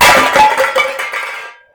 trap_tripwire_cans_1.ogg